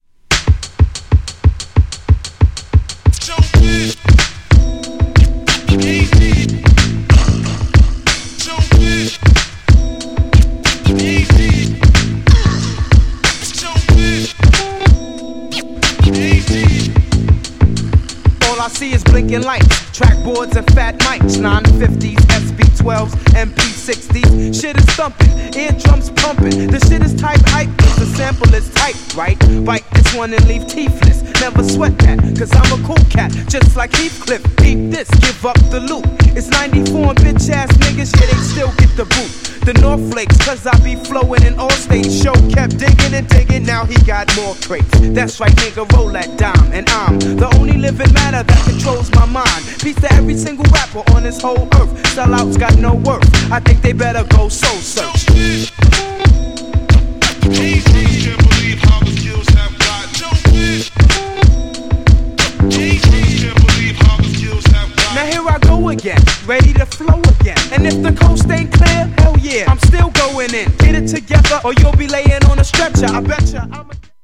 GENRE Hip Hop
BPM 91〜95BPM